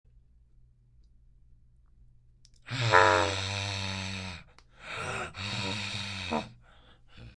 Angry Fox Sound Button - Free Download & Play